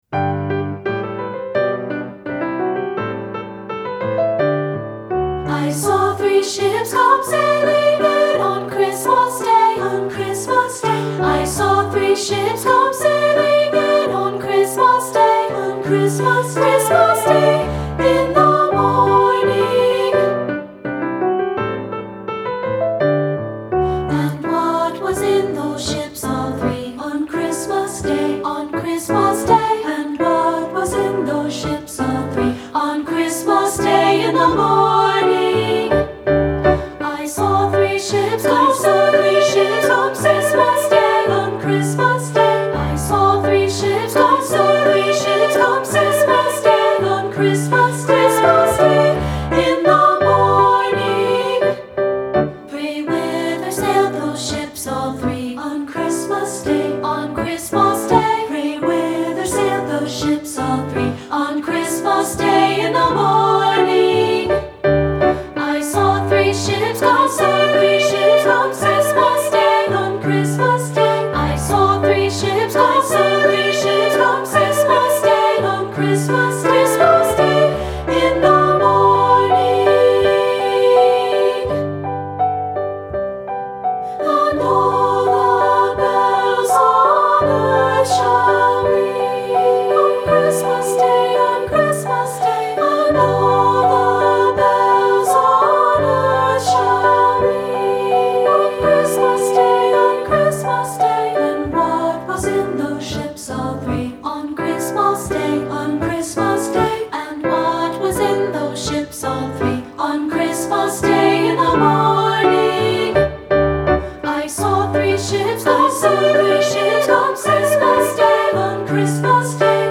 Choral Christmas/Hanukkah